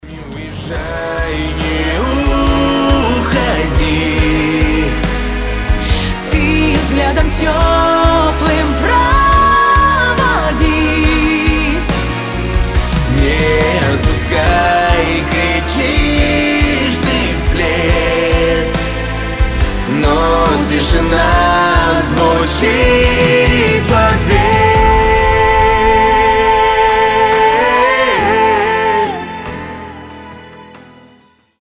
громкие
грустные
дуэт
медленные